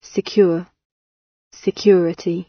On the other hand, the audio for security exhibits contemporary smoothing, sɪkjʉːrɪtɪj, with ʉwə smoothed to ʉː before r: